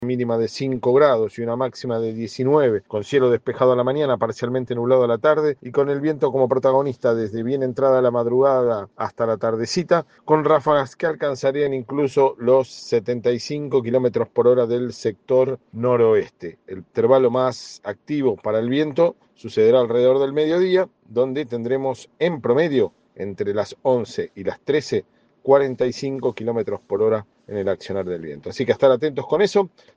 En diálogo con DE LA BAHÍA, el profesional señaló que el viento “será protagonista desde bien entrada la madrugada hasta la tardecita, con ráfagas que alcanzarían los 75 kilómetros por hora del sector noroeste”.